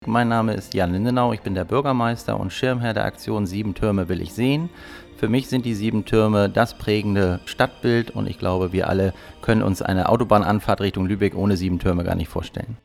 Statement_Buergermeister_Jan_Lindenau_roh.mp3